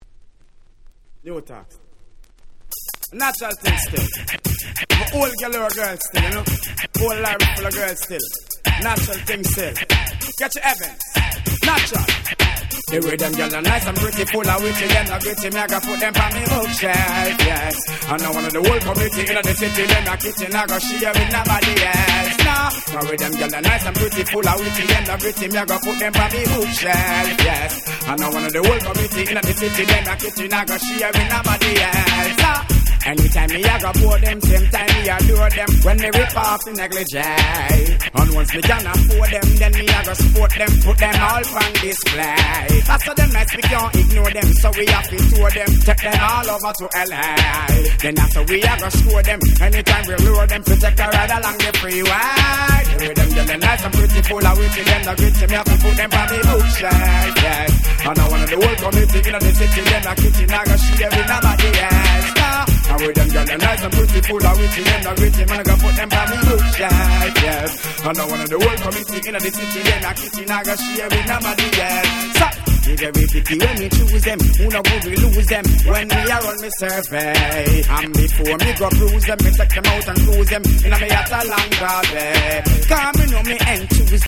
Dancehall Reggae Classics !!